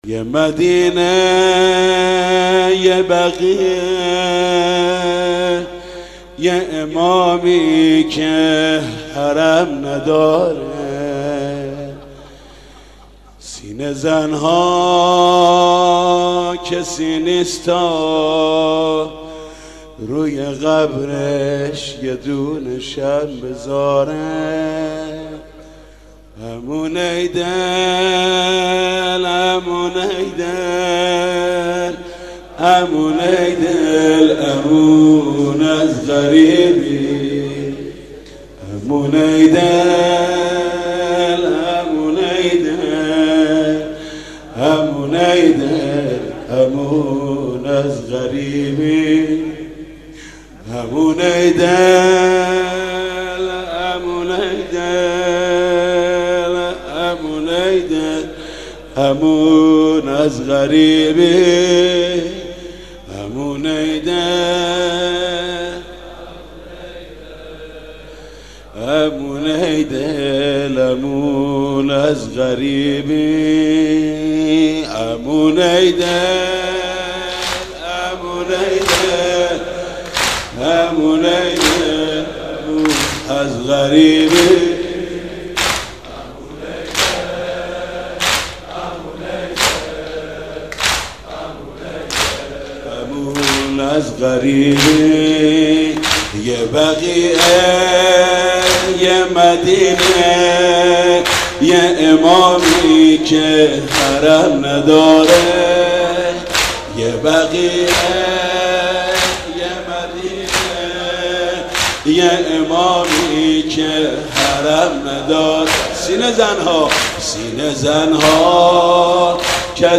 «ویژه مناسبت تخریب بقیع» زمینه: یه مدینه یه بقیع یه امامی که حرم نداره